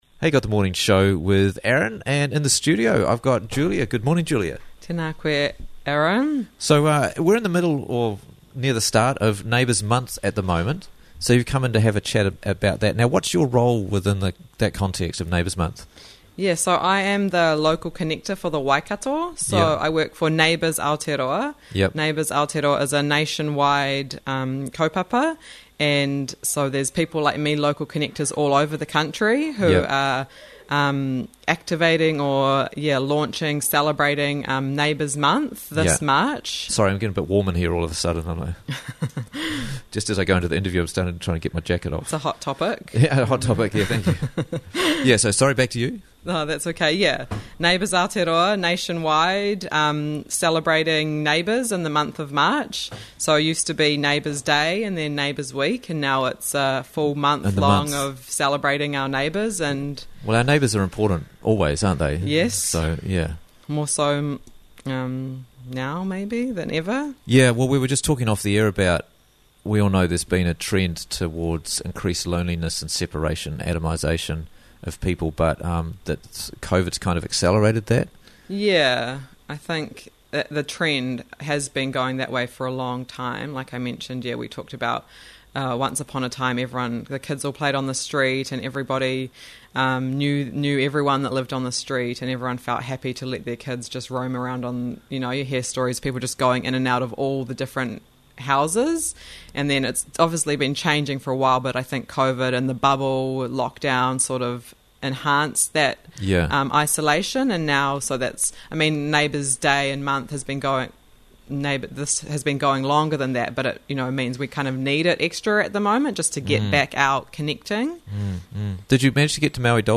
Prizes to be Won During Neighbours Month - Interviews from the Raglan Morning Show